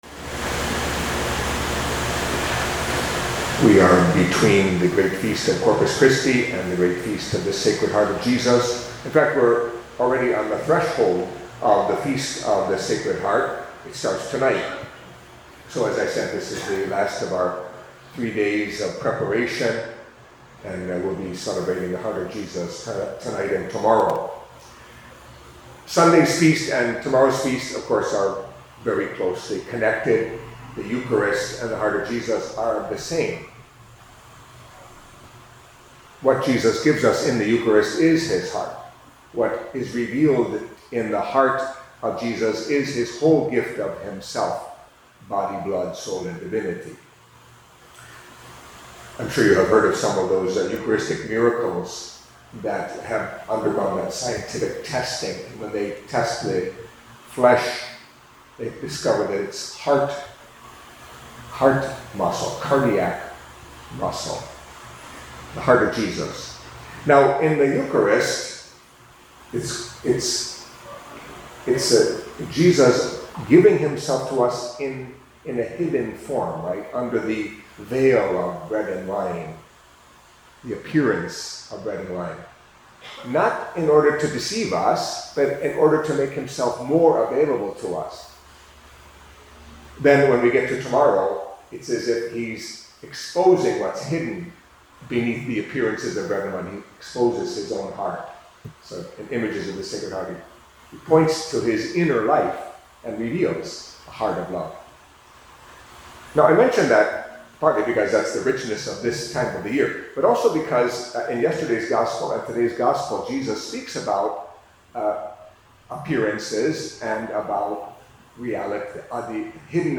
Catholic Mass homily for Thursday of the Twelfth Week in Ordinary Time